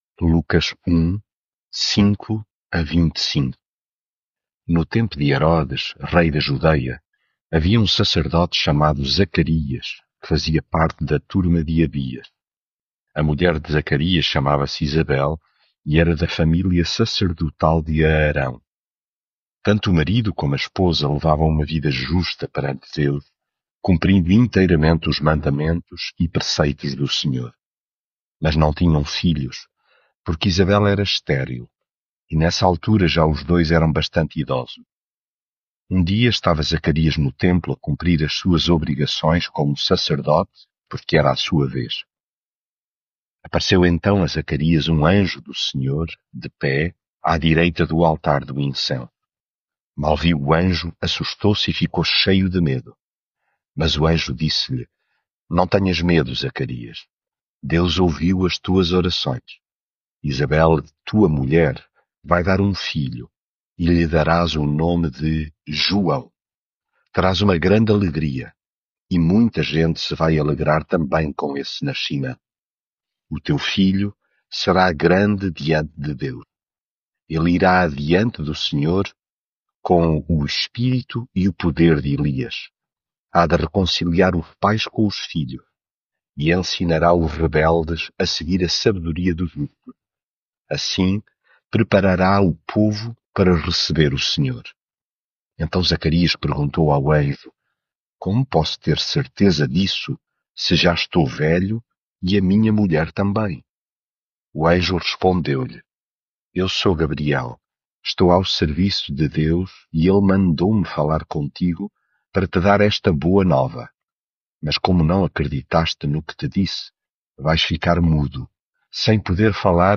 devocional Lucas leitura bíblica No tempo de Herodes, rei da Judeia, havia um sacerdote chamado Zacarias, que fazia parte da turma de Abias.